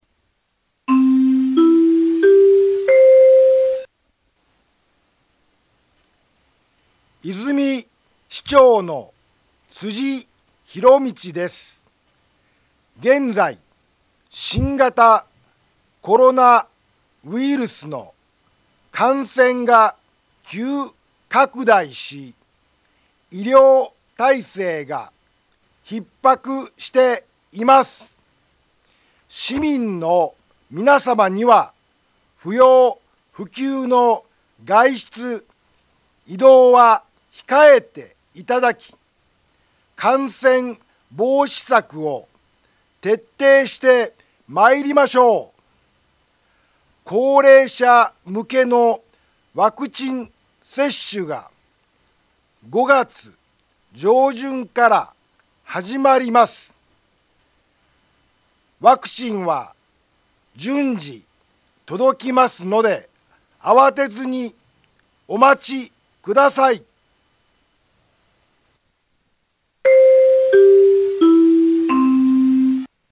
Back Home 災害情報 音声放送 再生 災害情報 カテゴリ：通常放送 住所：大阪府和泉市府中町２丁目７−５ インフォメーション：和泉市長の辻 ひろみちです。 現在、新型コロナウイルスの感染が急拡大し、医療体制が逼迫しています。